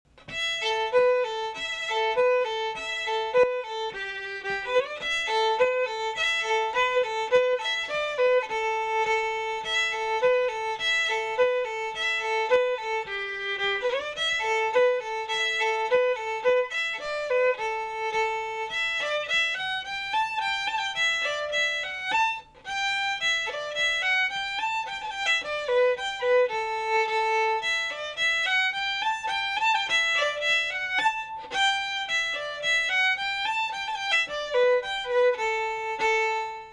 Session Tunes